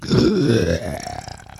zombie-7.ogg